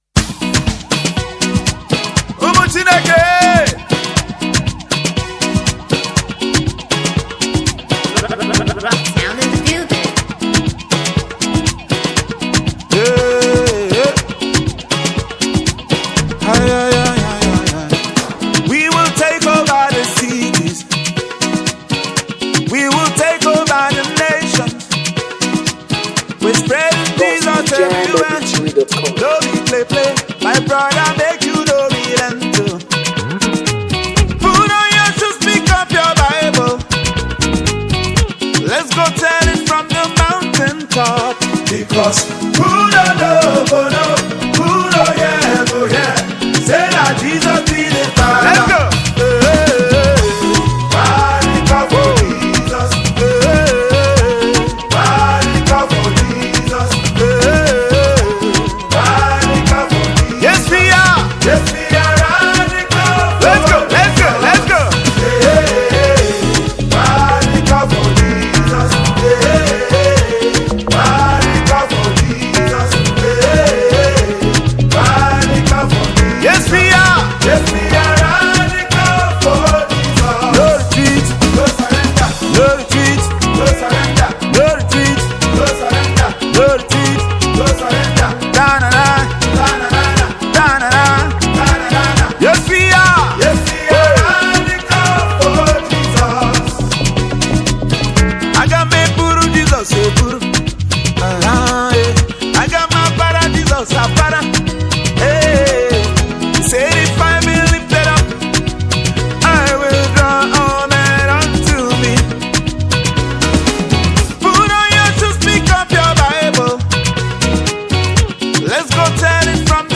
Featuring powerful tracks from anointed gospel ministers